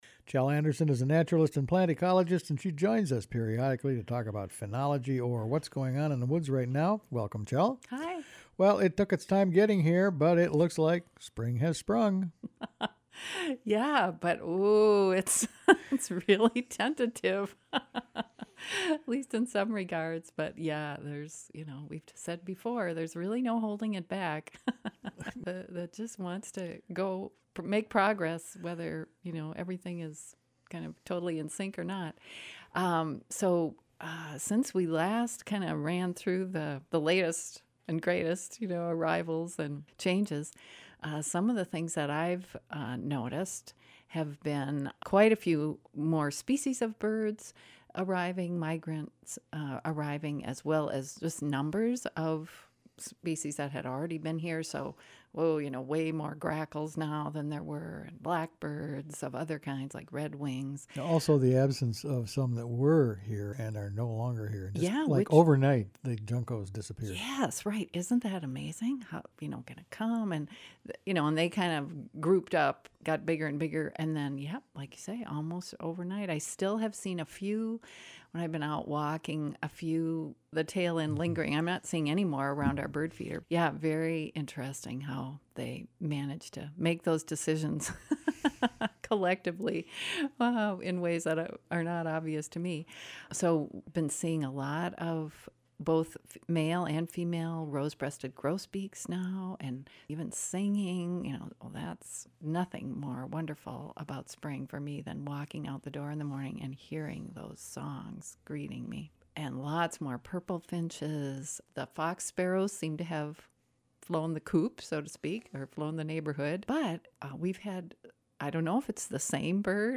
talks with naturalist